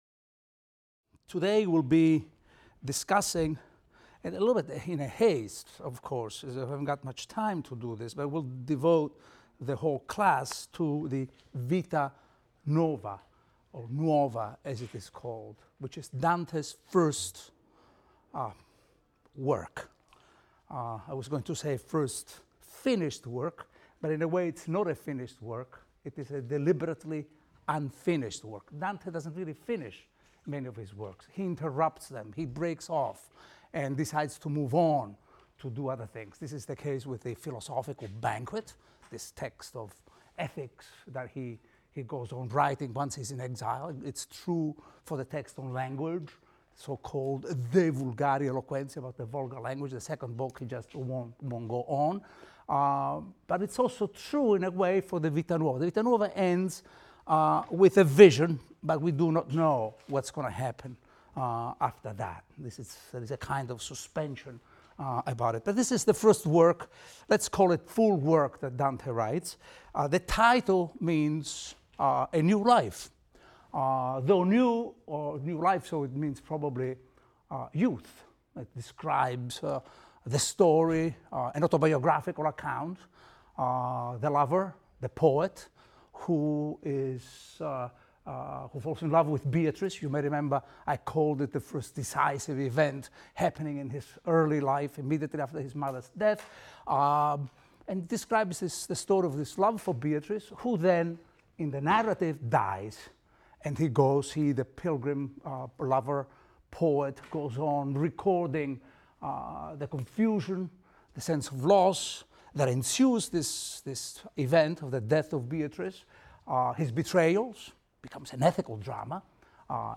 ITAL 310 - Lecture 2 - Vita Nuova | Open Yale Courses